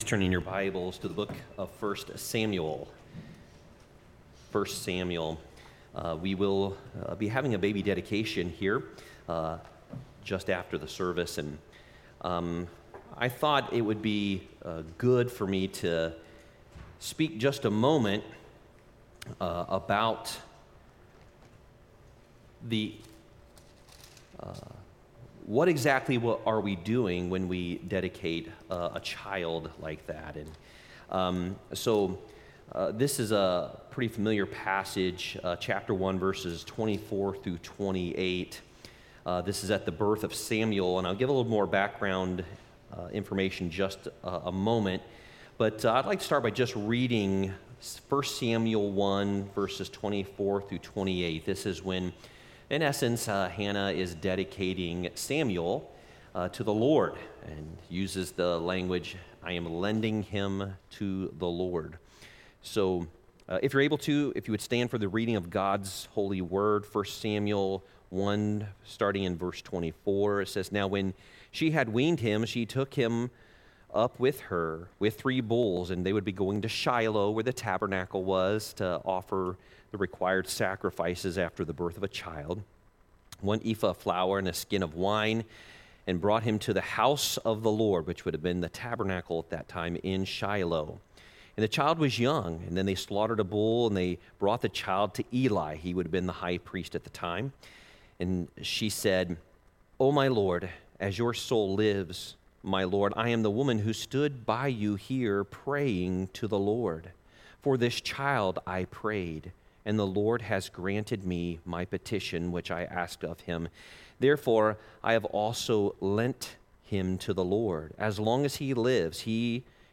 Worship Service 1/28/24